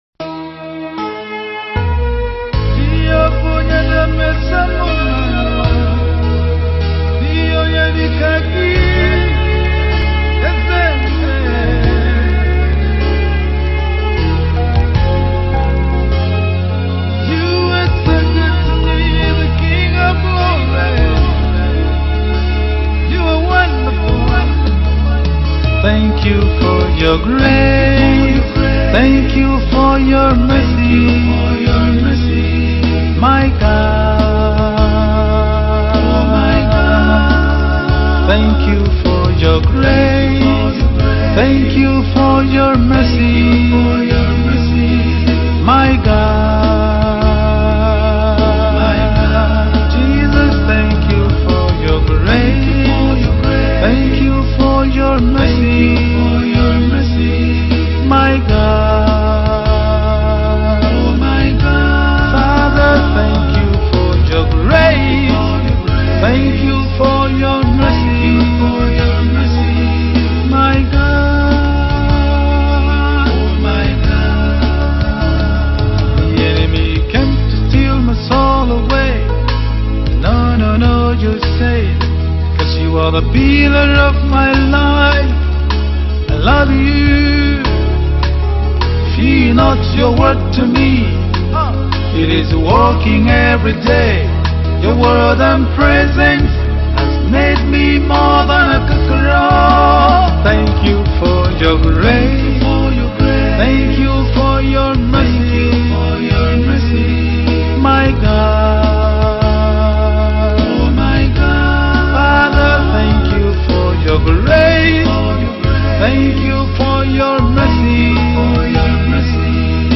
January 20, 2025 Publisher 01 Gospel 0